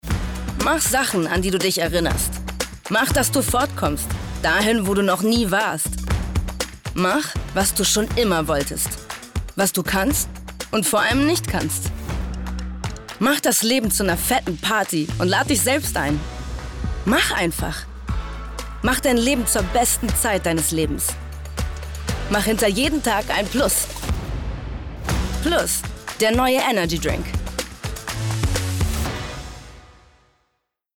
dunkel, sonor, souverän, markant
Mittel minus (25-45)
Norddeutsch
Energydrink Werbung